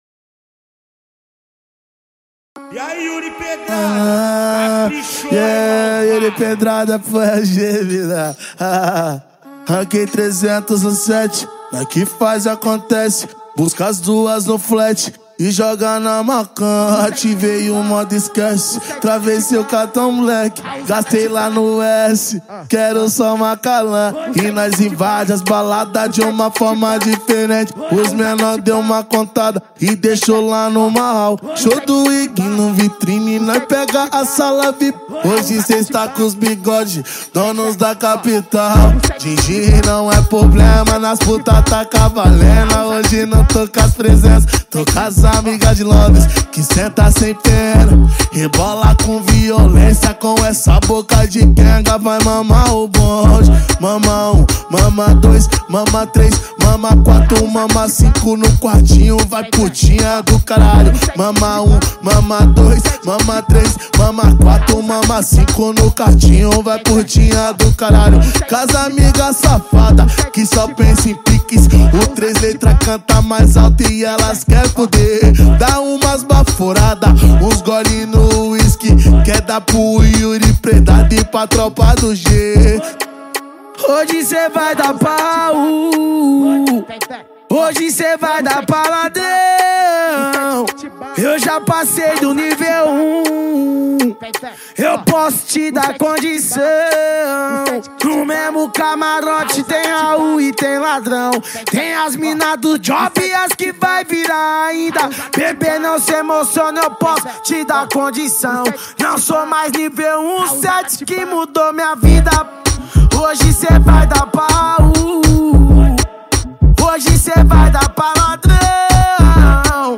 2024-09-30 21:48:34 Gênero: Funk Views